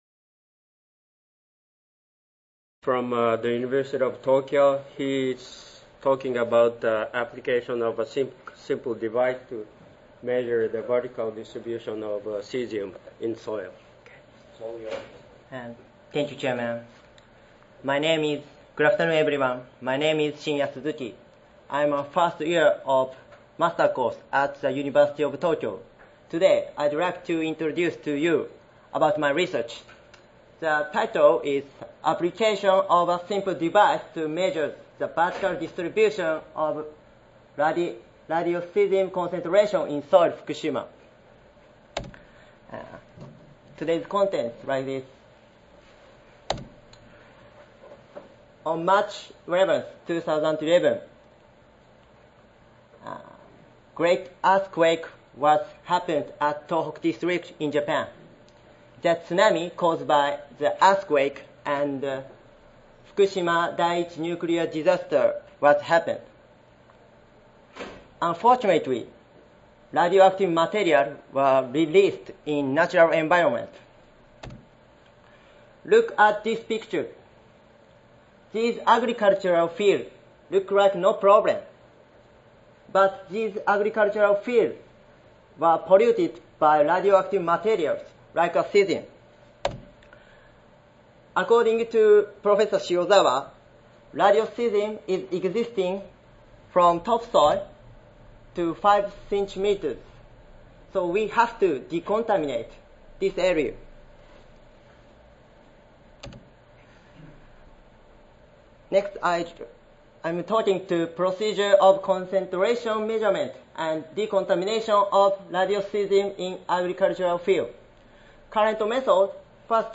Session: Battles of Soil Scientists in Fukushima, Japan (ASA, CSSA and SSSA International Annual Meetings)
Recorded Presentation